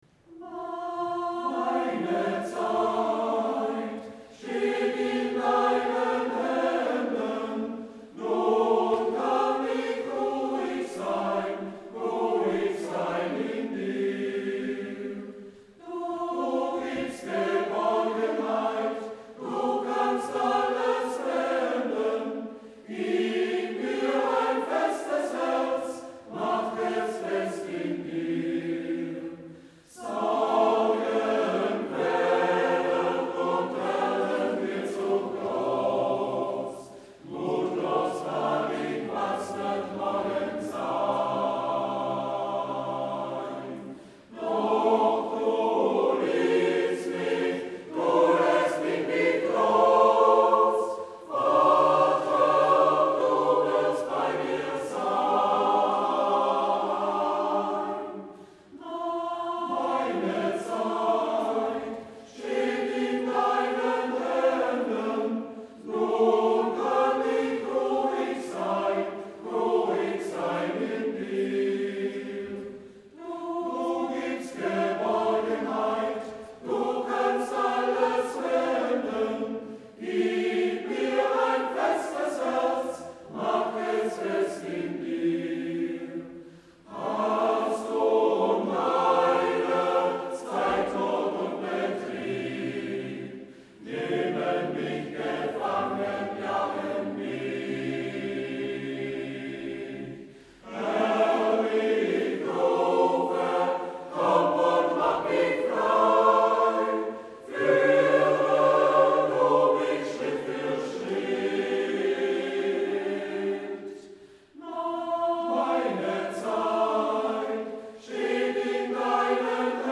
Im November 2008 konnten wir einige Sängerinnen und Sänger in der Kirche ehren.